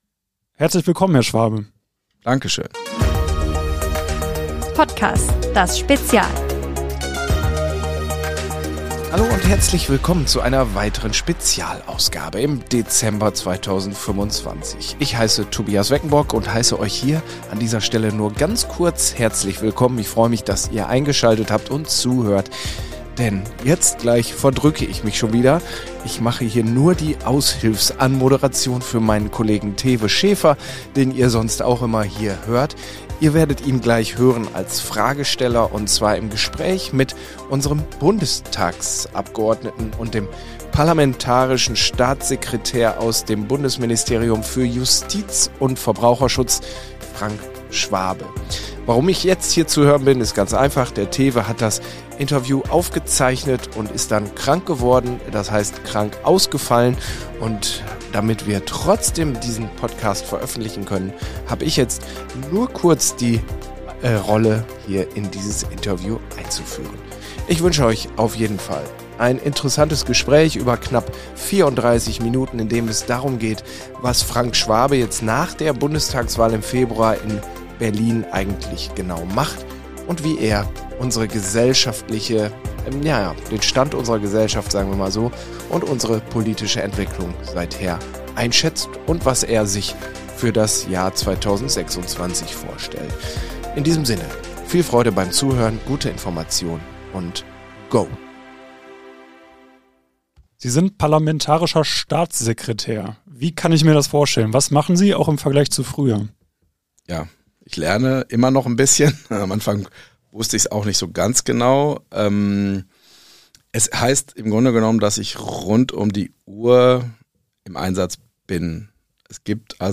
Spezial: Frank Schwabe im Regierungs-Interview ~ PottCAS - Der News-Podcast für Castrop-Rauxel Podcast
Jetzt war der 55-Jährige um Jahresabschluss bei uns im Studio.